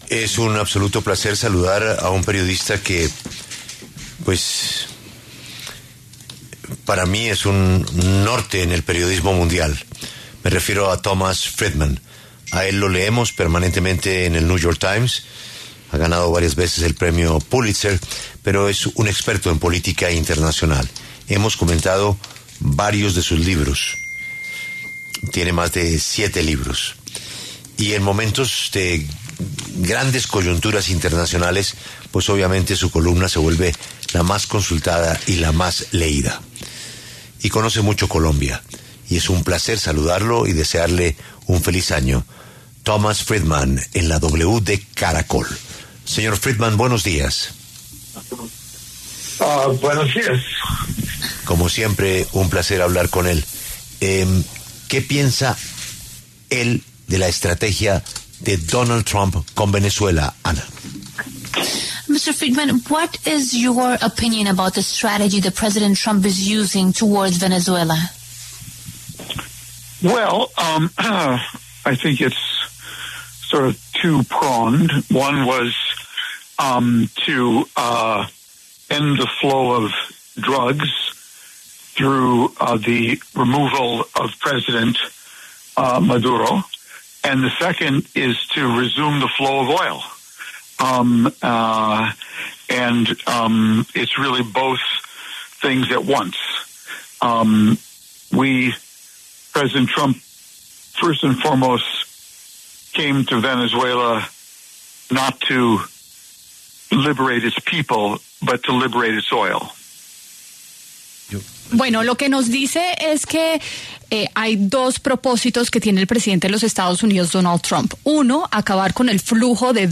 El autor, reportero y columnista de renombre internacional con tres premios Pulitzer, Thomas Friedman, dijo en La W que la operación militar de Estados Unidos en Venezuela no se trató solamente de petróleo, aunque este componente sí fue el tema principal.